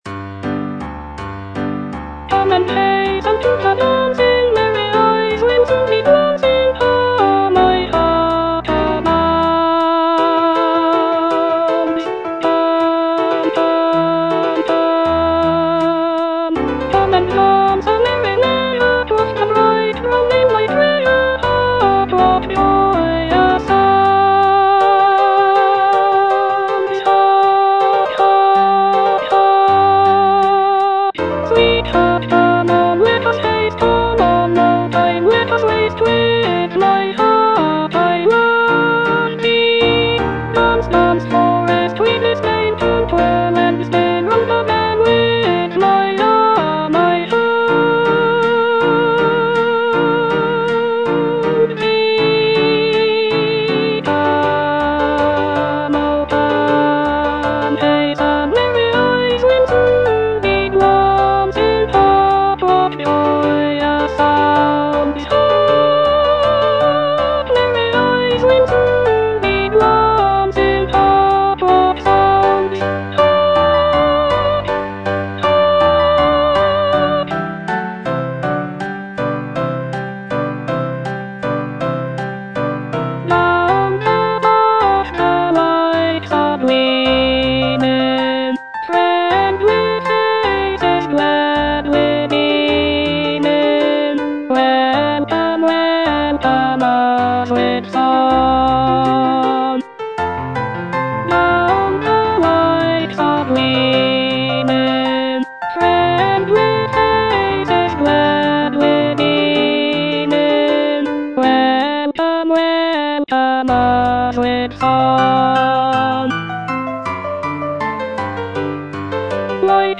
E. ELGAR - FROM THE BAVARIAN HIGHLANDS The dance - Alto (Voice with metronome) Ads stop: auto-stop
The music captures the essence of the picturesque landscapes and folk traditions of the area, with lively melodies and lush harmonies.